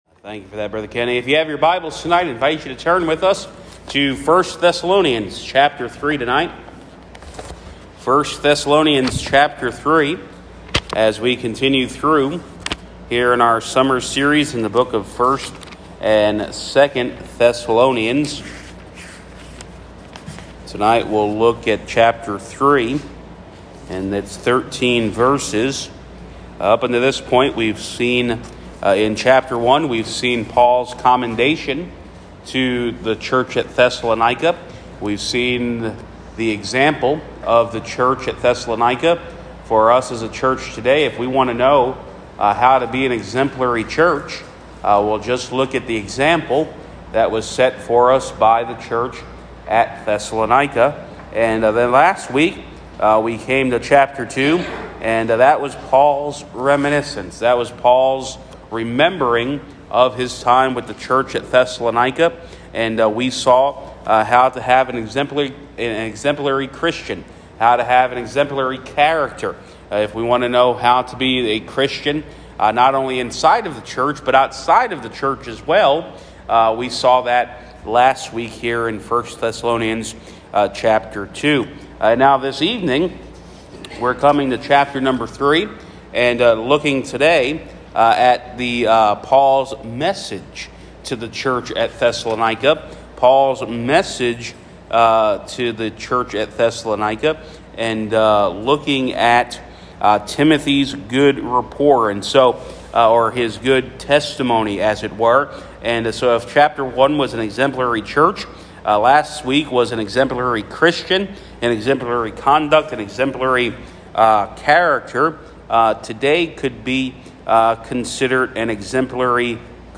Wednesday Evening Bible Study
Guest Speaker